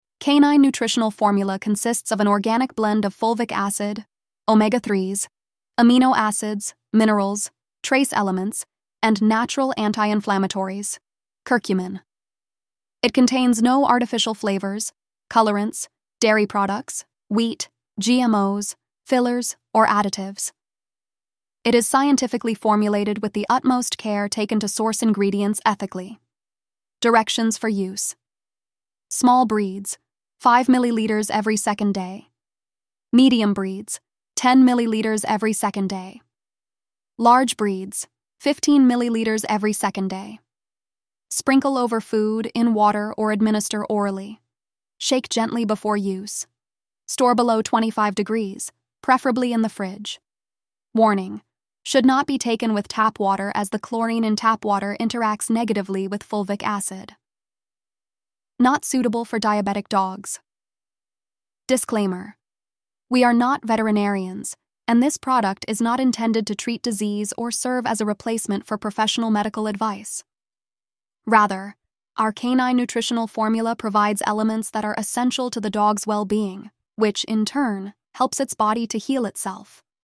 Narrated Product Information.
Oshun-Health-Canine-Voiceover.mp3